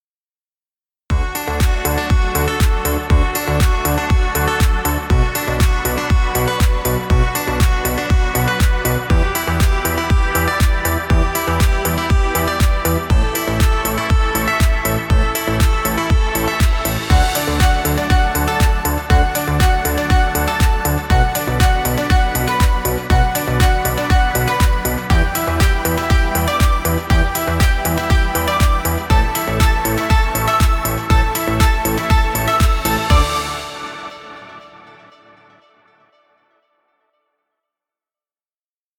Dance music.